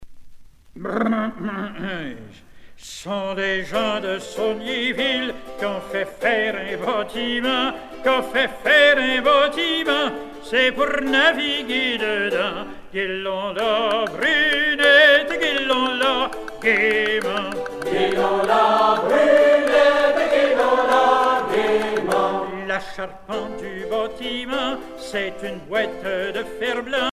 laisse
Pièce musicale éditée